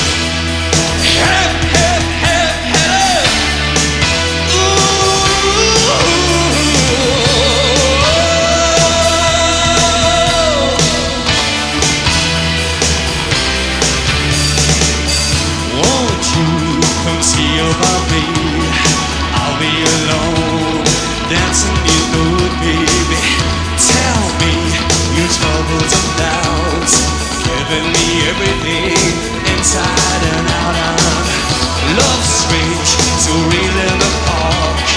Rock Pop
Жанр: Поп музыка / Рок